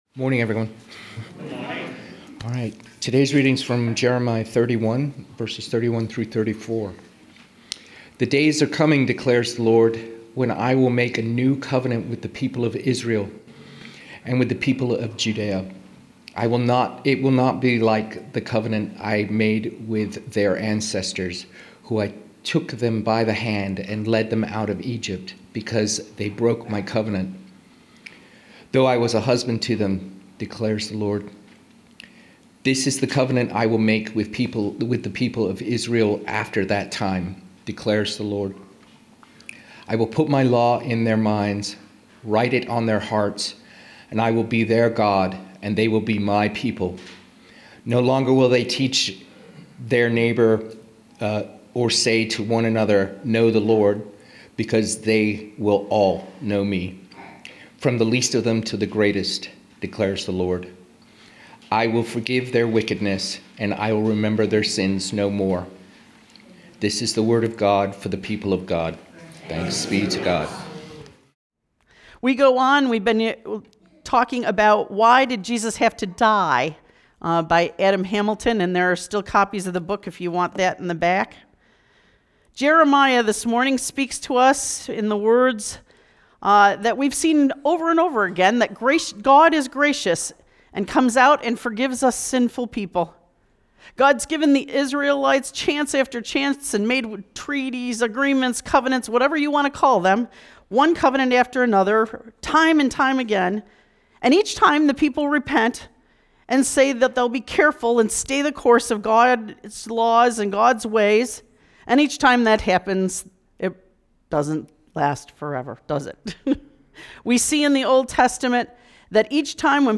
March 1, 2026 Sermon Audio